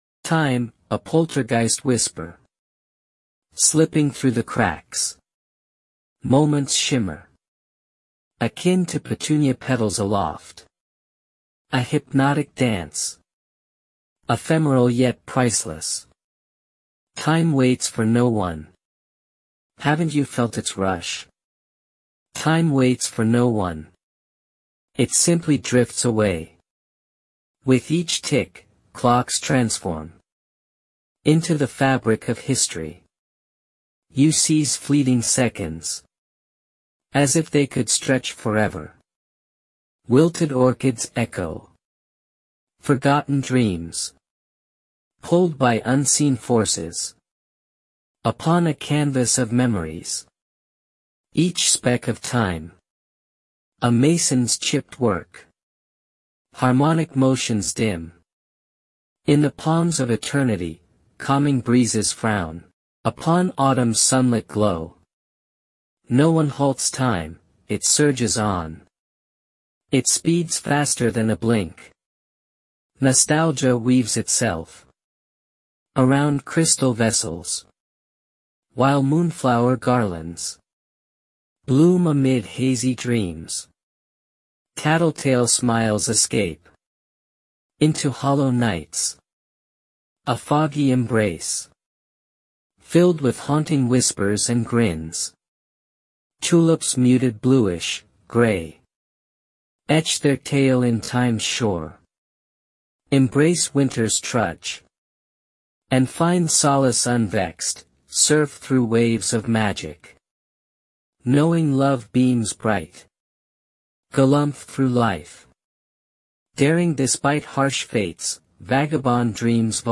The arrangement seems particularly good, and the scene at the start with the violinist and trench coat make me smile.